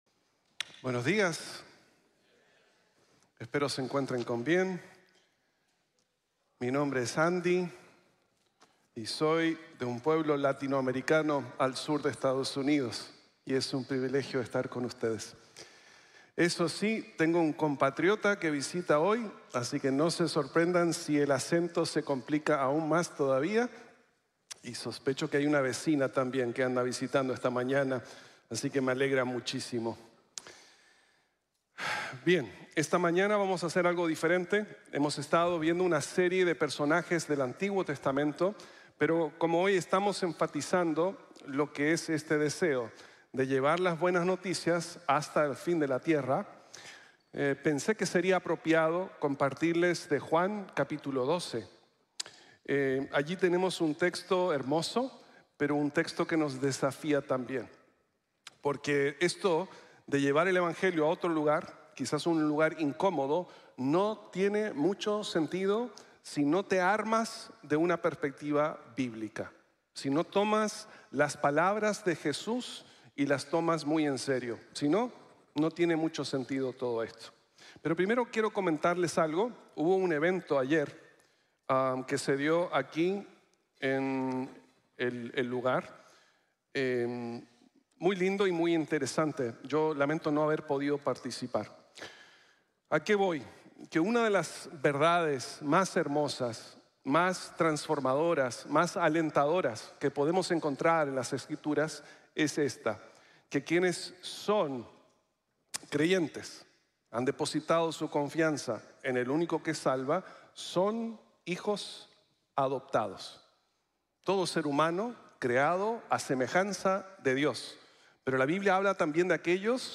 Piensa a Largo Plazo | Sermón | Grace Bible Church